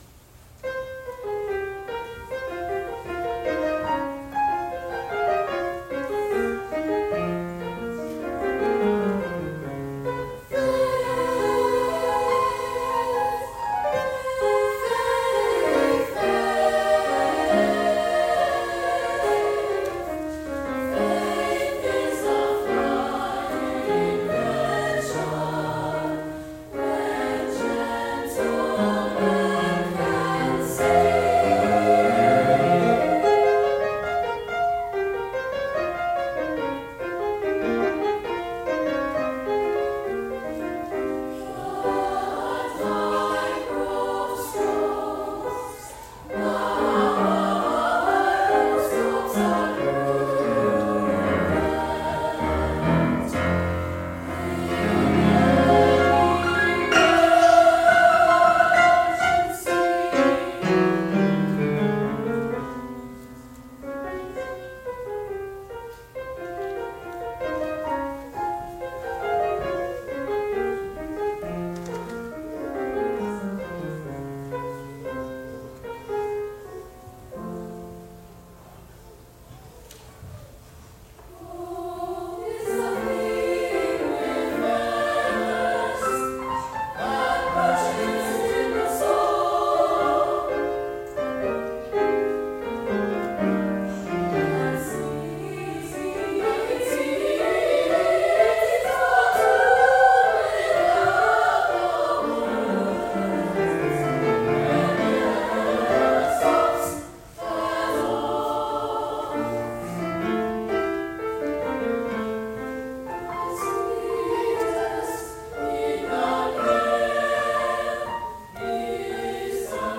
SSA chorus, piano